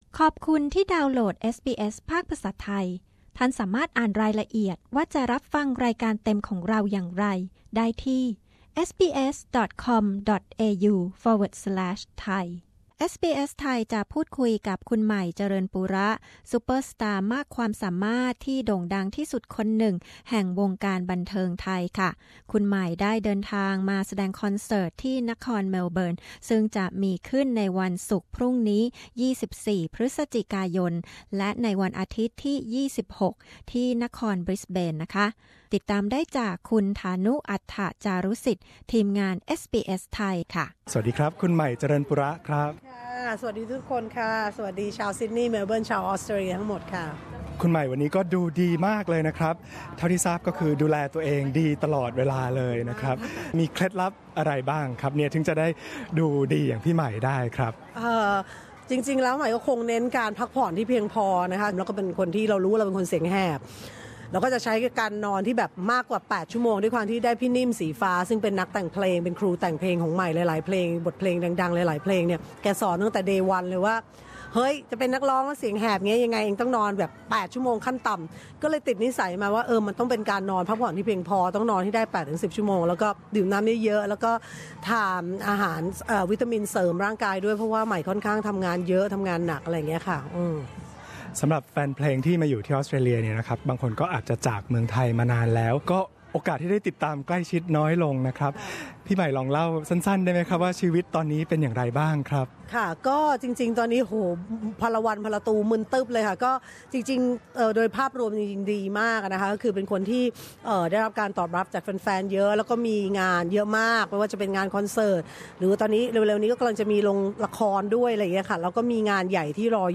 ใหม่ เจริญปุระ ที่สุดของเอนเตอร์เทนเนอร์แถวหน้า พาความเผ็ดครบเครื่องทุกความสามารถ มาเขย่าวงการป็อปร็อกกันอีกครั้งด้วยคอนเสิร์ตที่นครเมลเบิร์นและบริสเบนช่วงปลายเดือนพฤศจิกายน ซึ่งในโอกาสนี้คุณใหม่ยังได้พูดคุยกับรายการเอสบีเอสไทยเป็นกรณีพิเศษ เต็มอิ่มไปด้วยความสนุกสนาน อบอุ่นและเป็นกันเอง